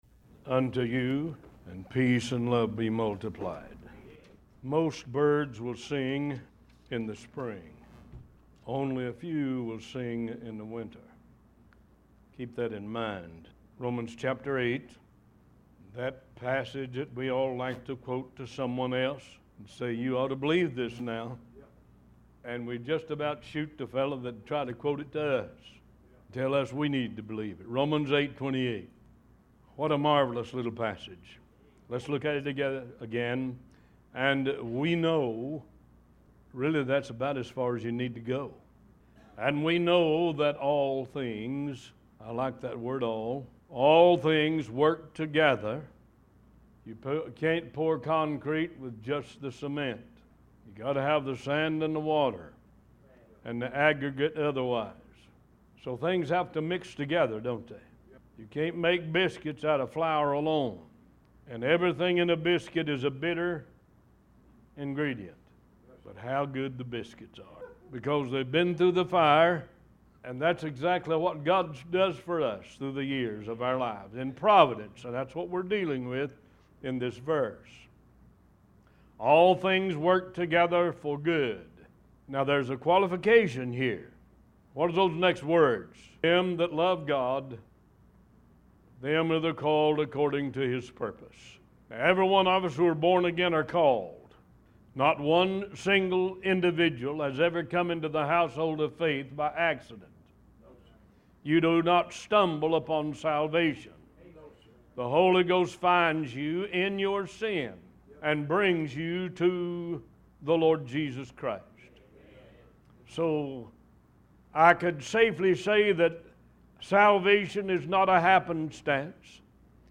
New Sermons published every Sunday and Wednesday at 11:30 AM EST